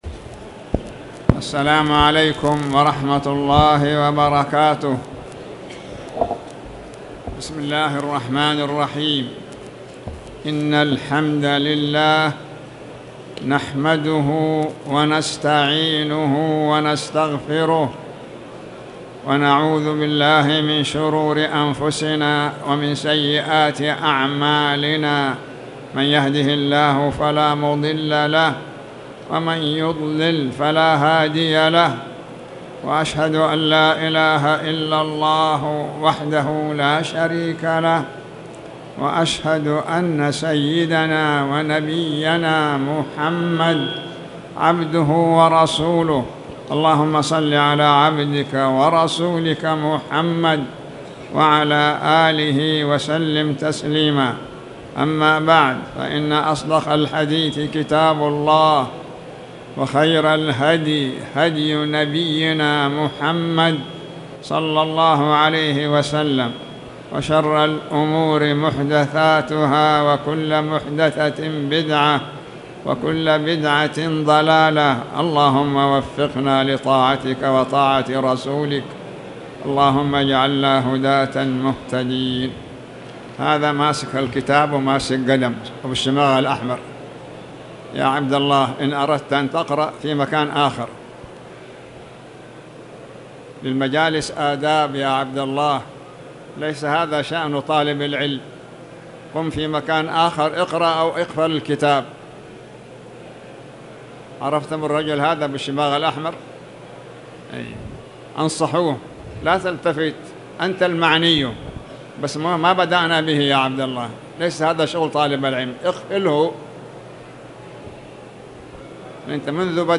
تاريخ النشر ٢٢ جمادى الآخرة ١٤٣٨ هـ المكان: المسجد الحرام الشيخ